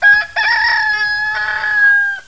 Hahn